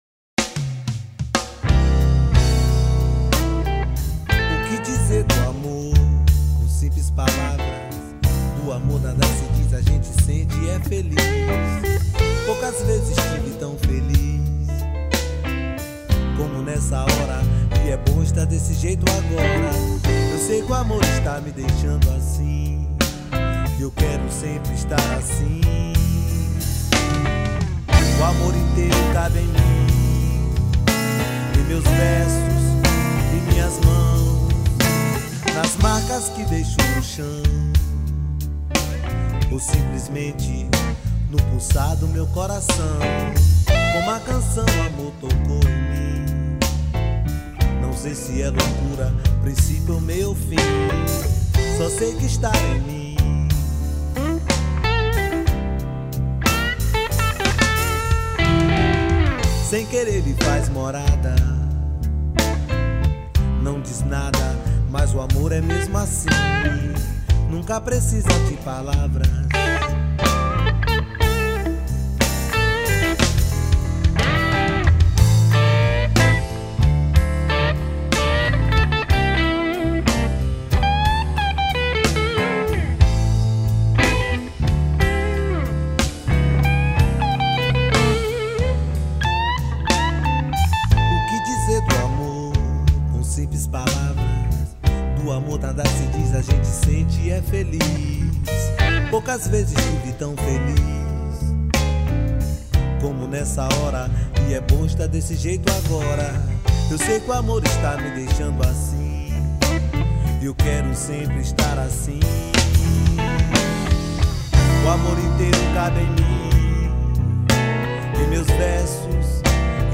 2616   03:21:00   Faixa: 8    Reggae